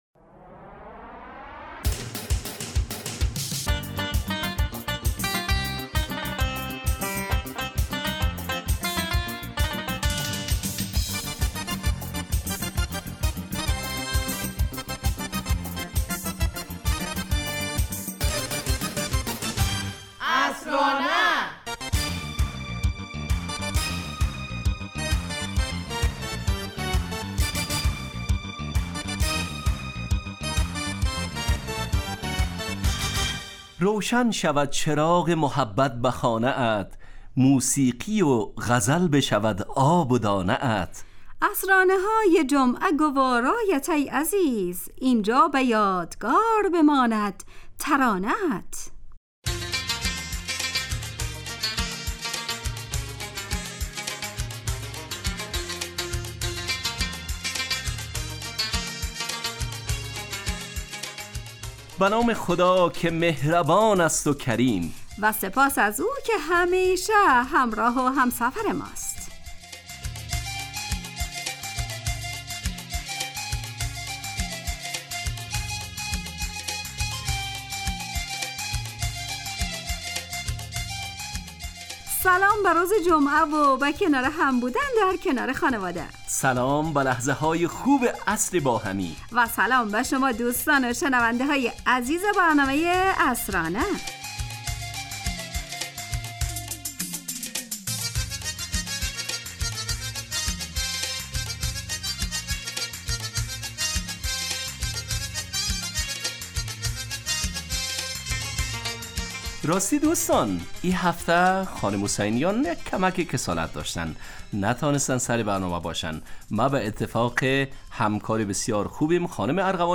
عصرانه برنامه ایست ترکیبی نمایشی که عصرهای جمعه بمدت 35 دقیقه در ساعت 17:55 دقیقه به وقت افغانستان پخش می شود و هرهفته به یکی از موضوعات اجتماعی و فرهنگی مرتبط با جامعه افغانستان می پردازد.